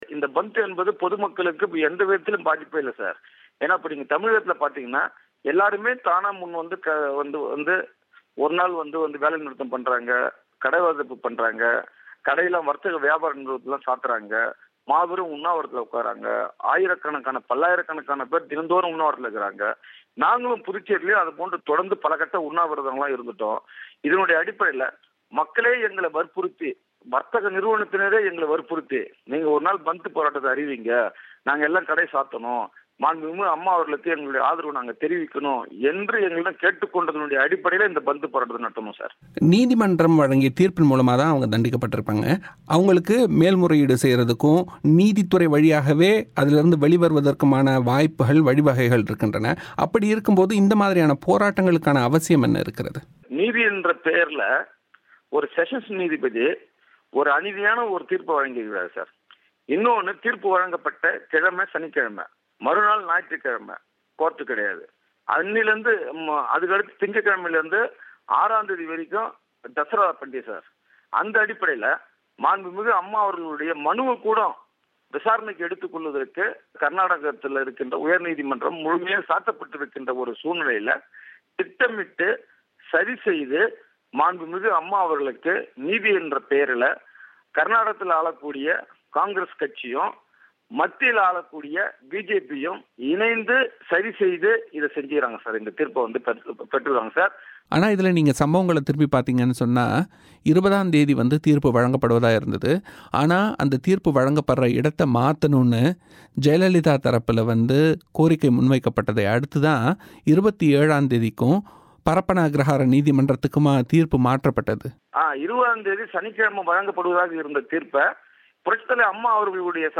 பொதுமக்களின் முழு ஆதரவோடு இந்த முழு கடையடைப்பு நடத்தப்பட்டுள்ளது என்றும், இந்த முழு அடைப்பினால் பொதுமக்களுக்கு எவ்வித பாதிப்பு இல்லை என்றும் புதுச்சேரி அதிமுக சட்டமன்றத் துணைத்தலைவரான அன்பழகன் தமிழோசையிடம் தெரிவித்தார்.